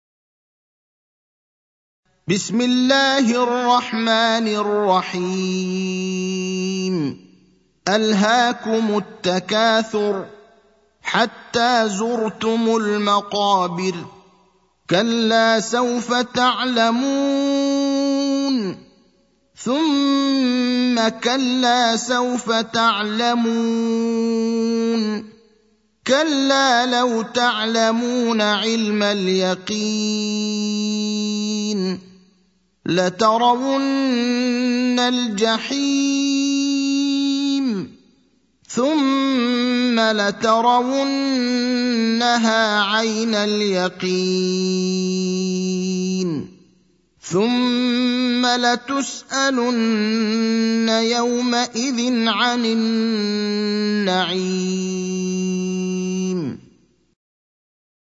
المكان: المسجد النبوي الشيخ: فضيلة الشيخ إبراهيم الأخضر فضيلة الشيخ إبراهيم الأخضر التكاثر (102)